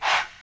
Supa Chant (1).wav